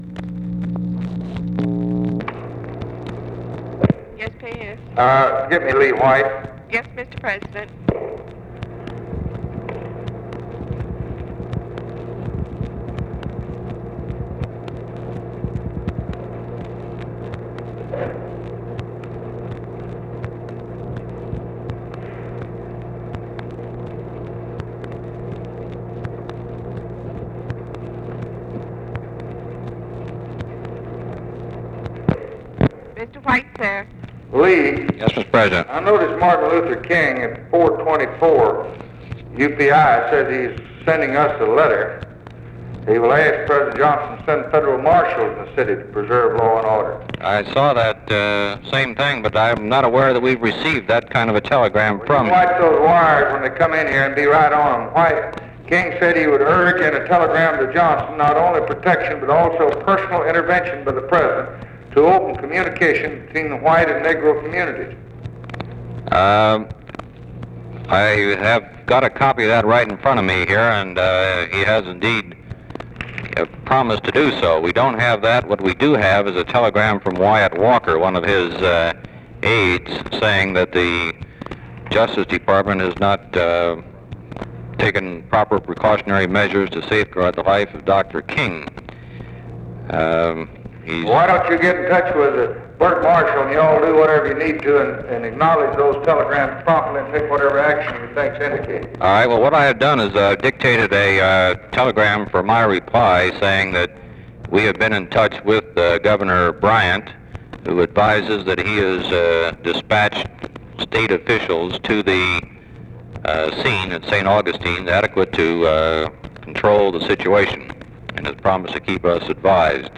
Conversation with LEE WHITE
Secret White House Tapes